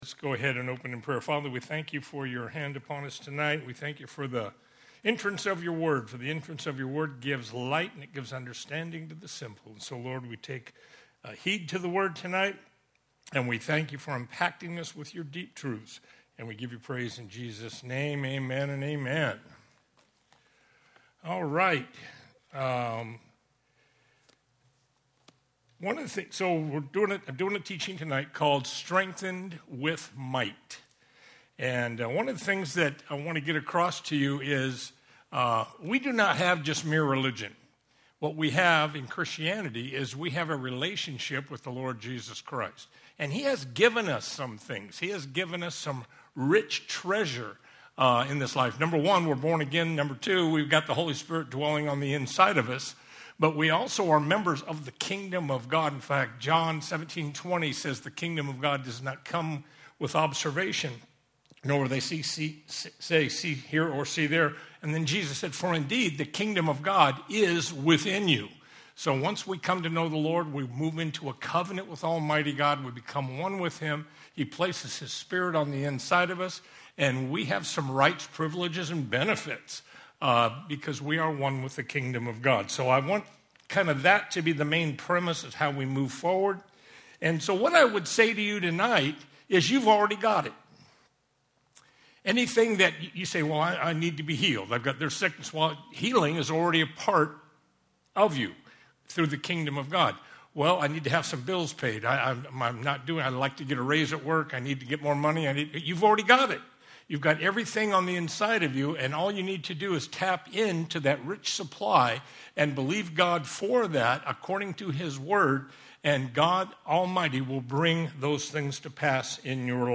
Sunday evening Bible study